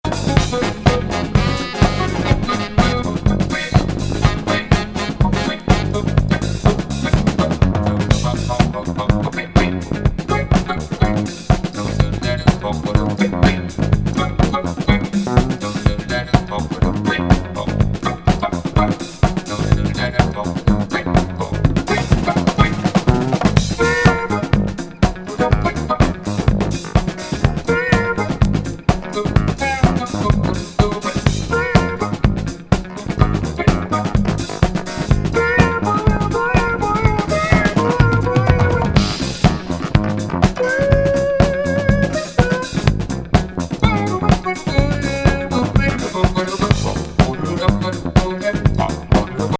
ダイレクト・カッティング盤!
ヴォコーダーが冴える